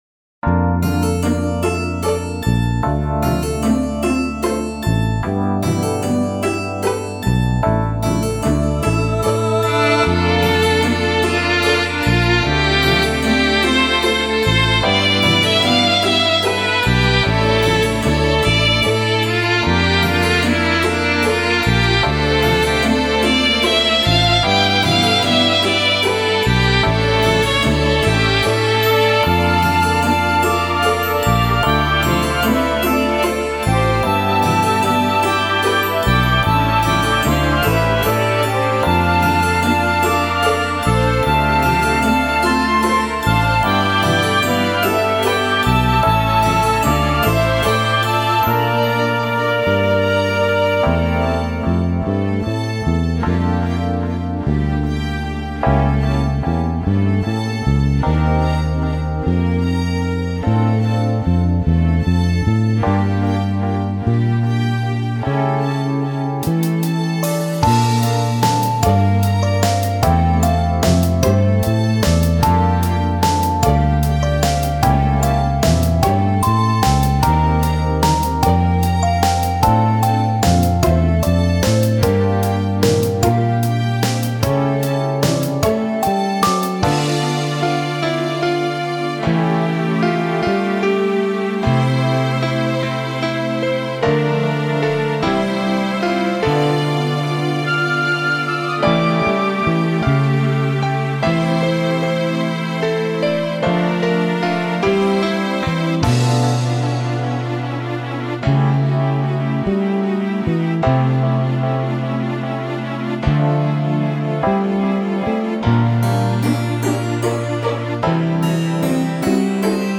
レトロな音質を表現
不穏でノスタルジックなダンジョン曲